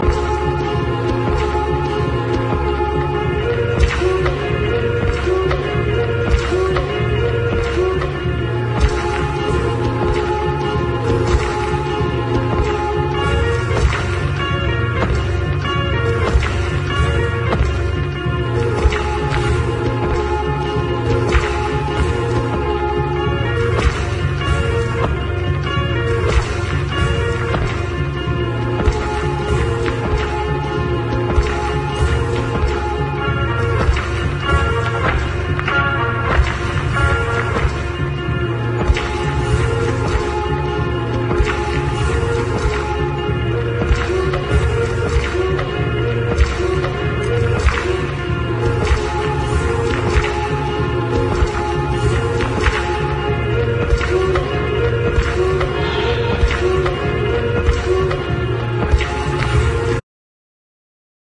キュートなエロ・モンド・シンセサイザー・コンピの決定盤！
70年代に制作されたチープでキッチュな、愛らしい電子音にとことん浸れます！